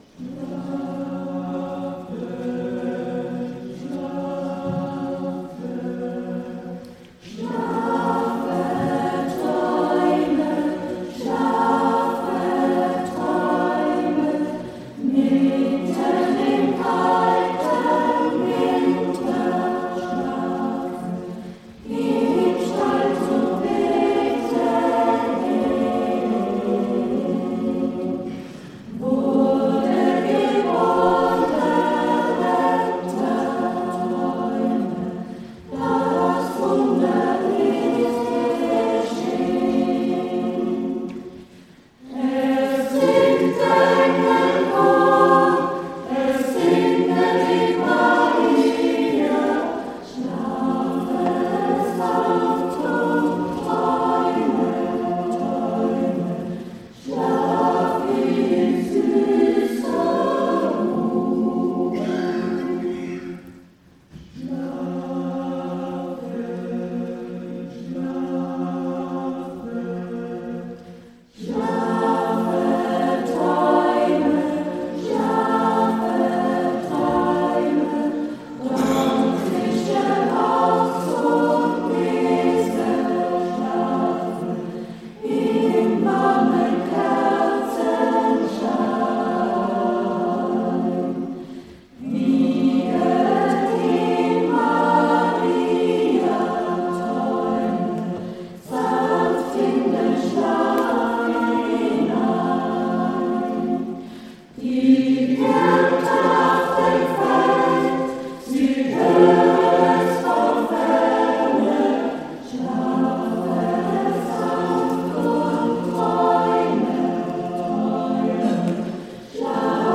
Musikalisch wurde der Gottesdienst vom Chor der Pfarre Resthof